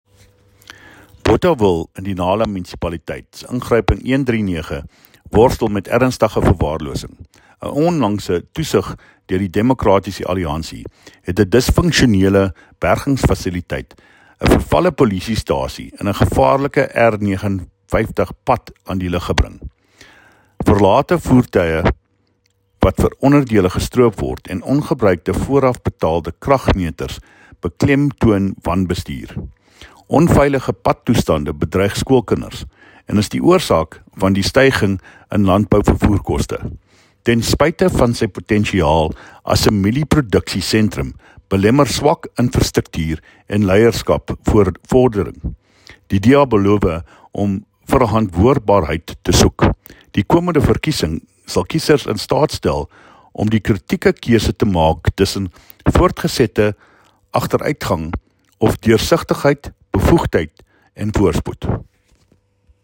Afrikaans soundbites by David Mc Kay MPL and